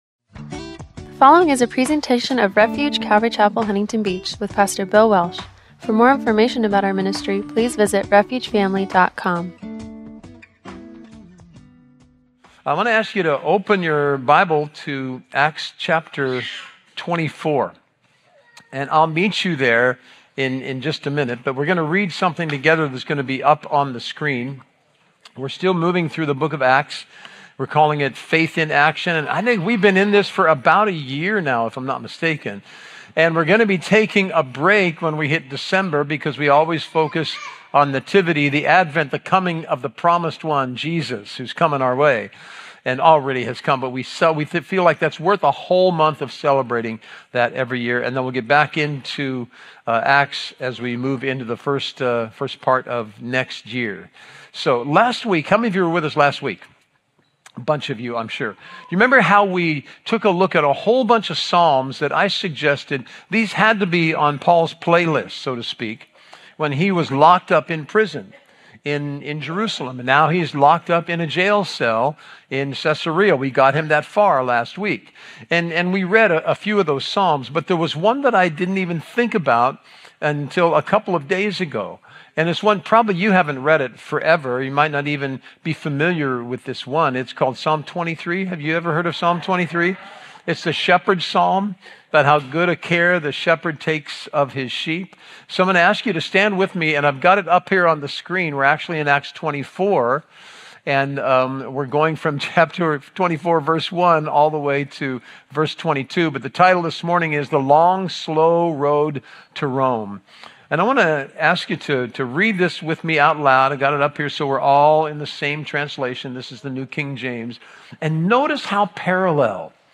Acts-Faith in Action – Audio-only Sermon Archive
Service Type: Sunday Morning